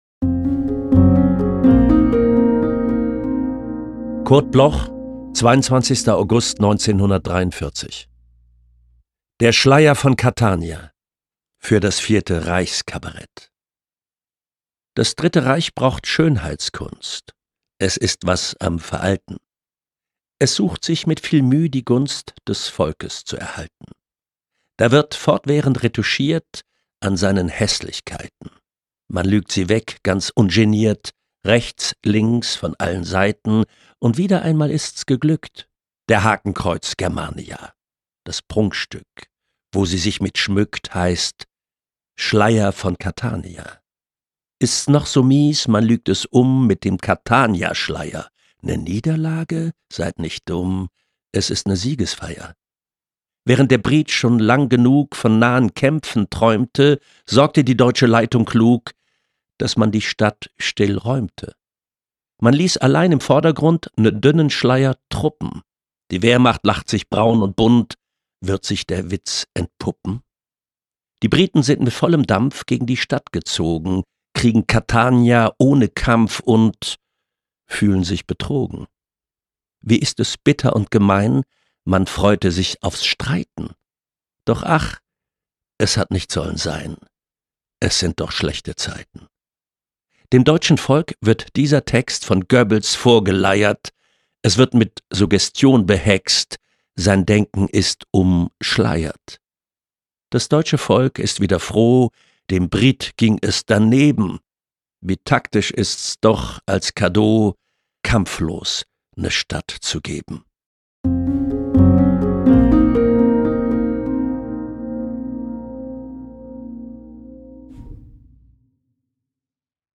Wolfram Koch (* 1962) is een Duits acteur en stemacteur.
naar het gedicht
Wolfram-Koch-Der-Schleier-mit-Musik.m4a